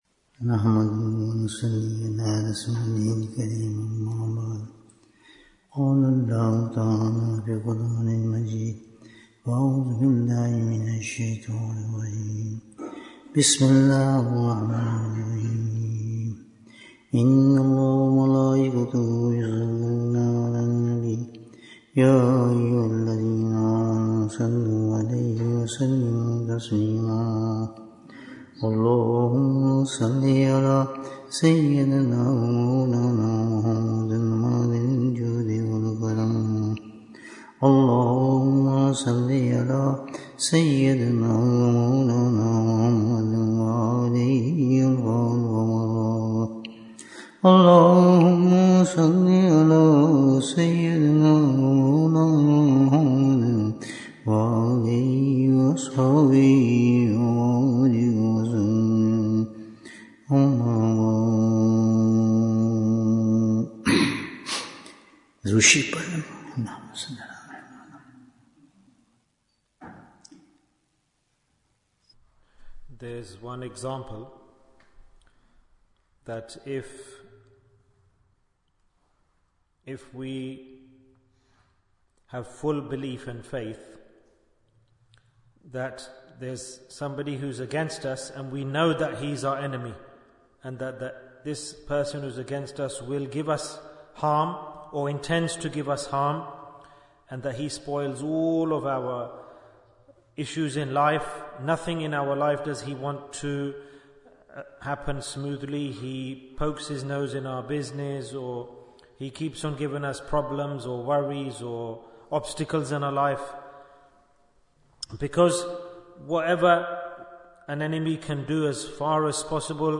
Why Do We Do Dhikr? Bayan, 120 minutes5th December, 2024